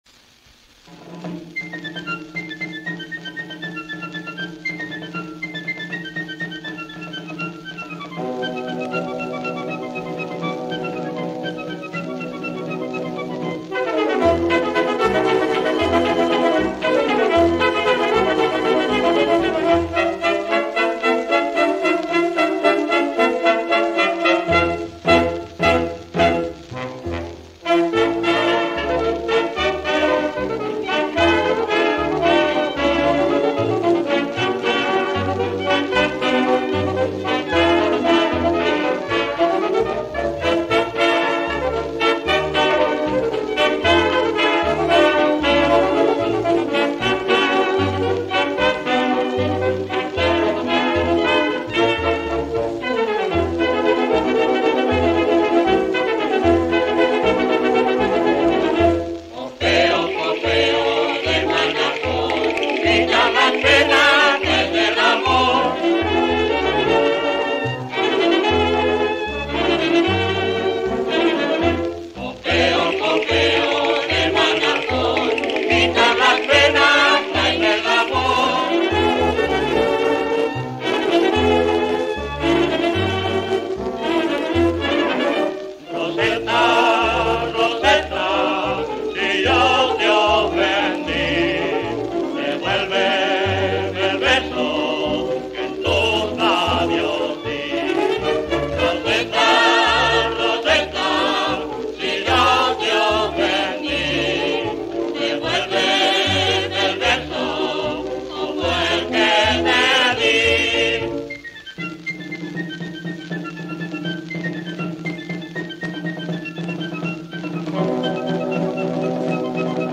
vals característico.